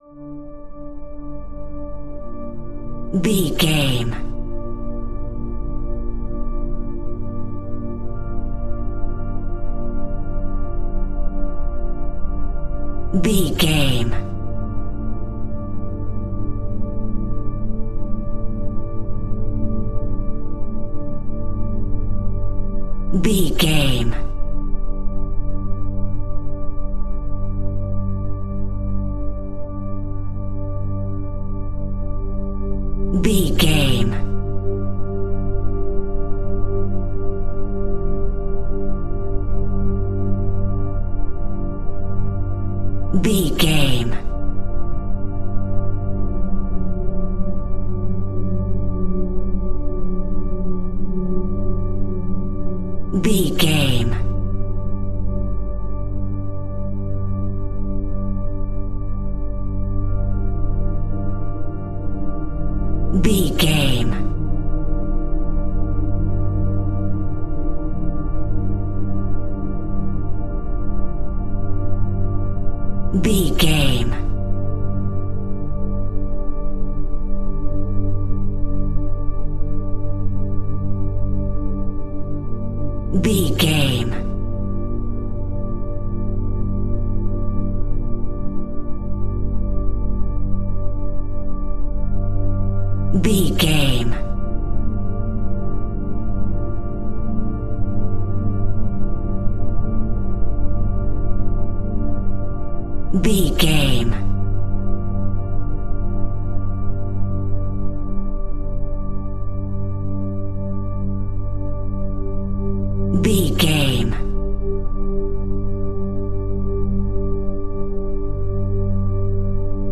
Haunted Cave Music.
Aeolian/Minor
Slow
ominous
suspense
eerie
synthesiser
ambience
pads
eletronic